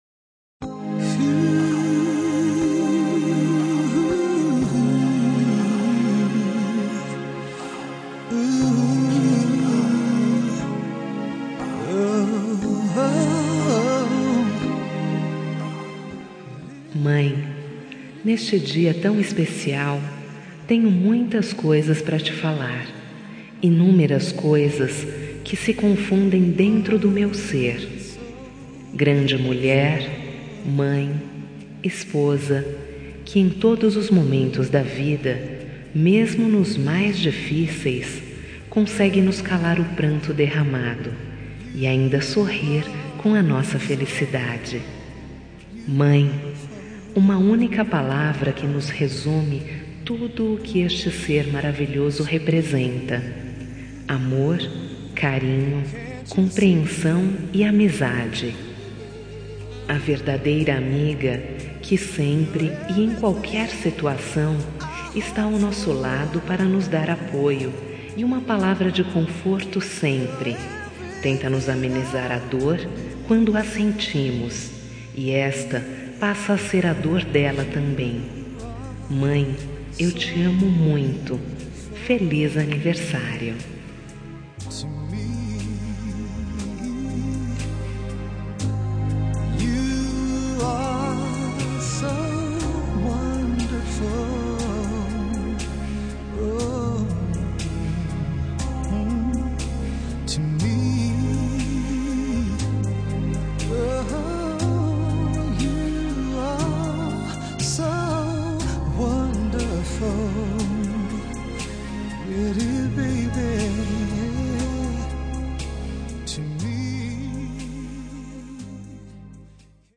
Telemensagem Aniversário de Mãe – Voz Feminina – Cód: 1402 –